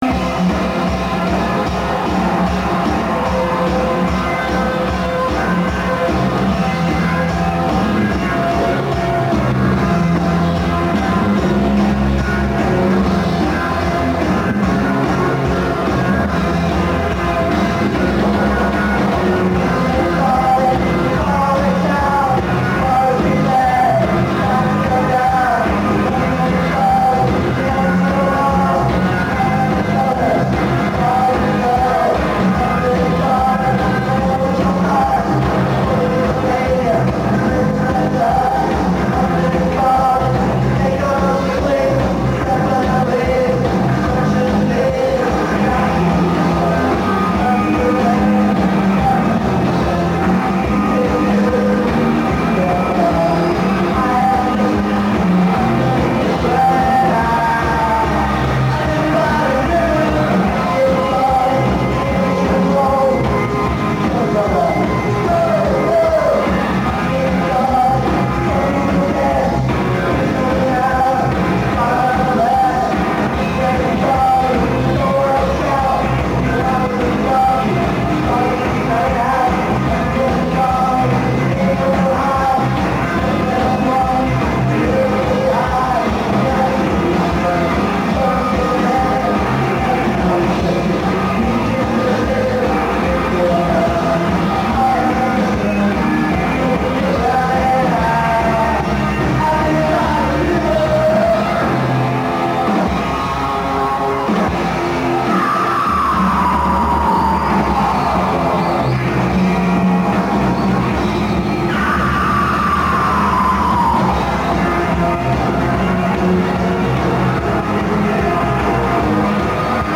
Tower Theatre, Philadelphia 3-06-80